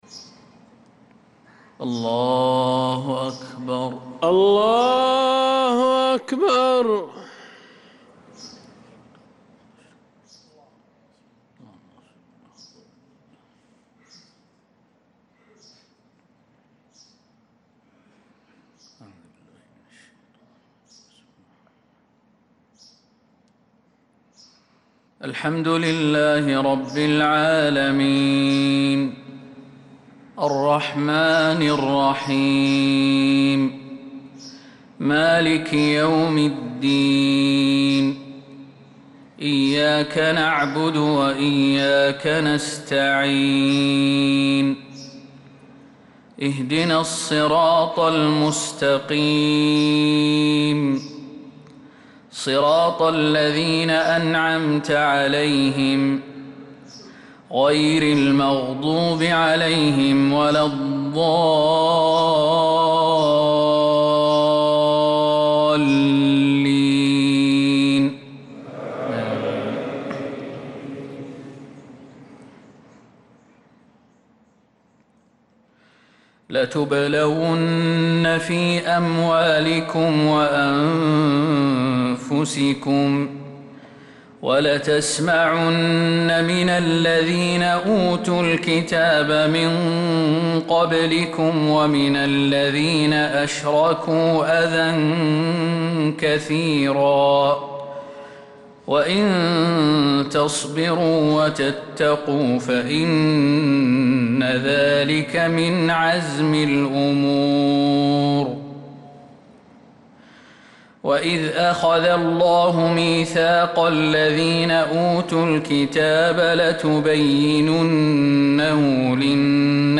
صلاة الفجر للقارئ خالد المهنا 20 رجب 1446 هـ
تِلَاوَات الْحَرَمَيْن .